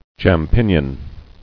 [cham·pi·gnon]